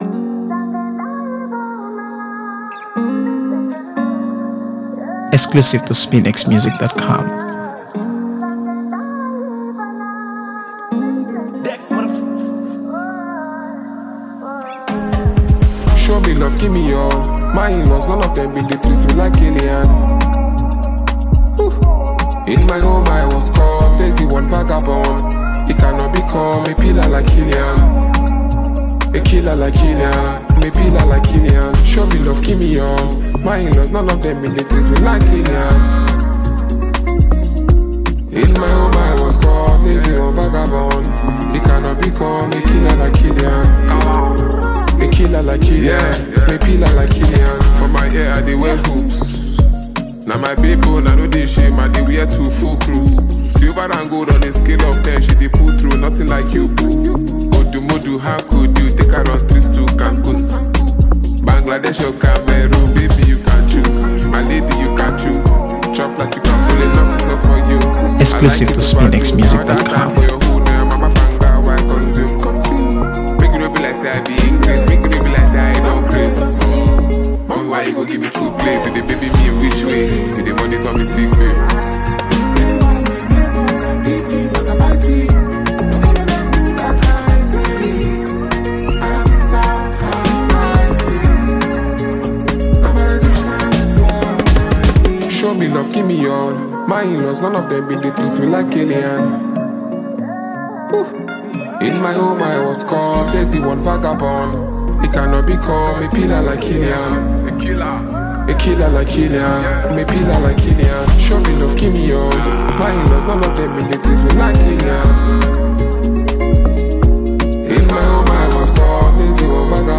AfroBeats | AfroBeats songs
delivers a high-energy blend of grit and charisma
fierce delivery and storytelling